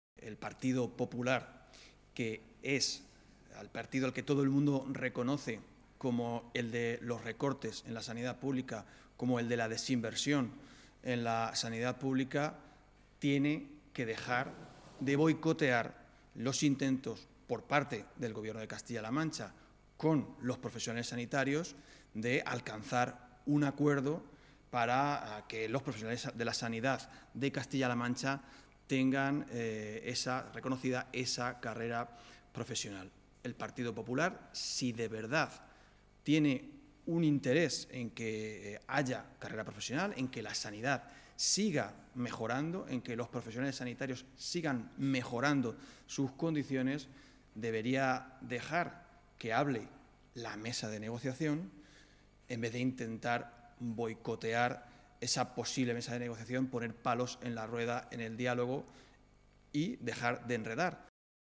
En rueda de prensa en la sede regional del PSOE, el diputado del grupo socialista en las Cortes de Castilla-La Mancha, Antonio Sánchez Requena, ha apuntado “si el PP de Núñez quisiese que esto avanzase, seguramente callaría un poco más y dejaría que hable la mesa de negociación”.
PPDejeDeBoicotearNegociacionesSanitarios_AntonioSanchezRequena.mp3.mp3